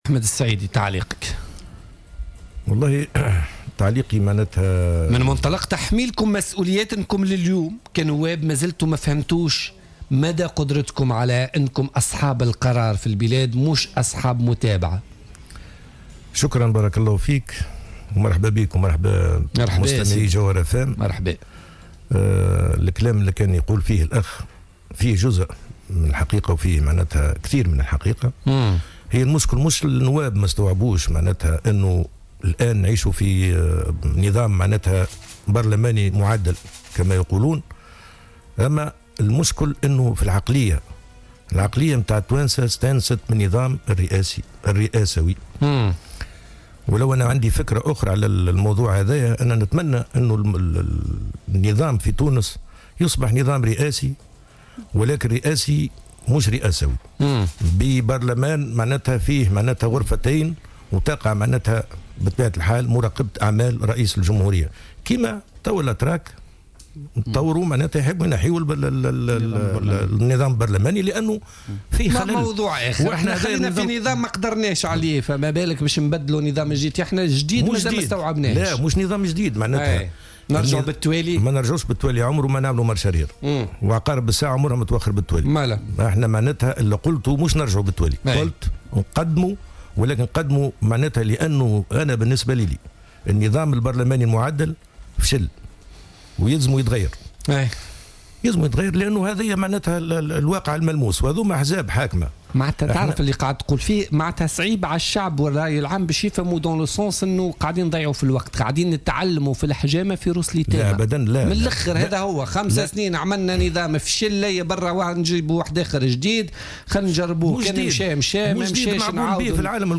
أكد النائب عن حزب المبادرة أحمد السعيدي ضيف بوليتيكا اليوم الاثنين 23 ماي 2016 أنه لايحبذ الحضور في جلسات مساءلة الوزراء التي تتم في جلسات عامة داخل المجلس .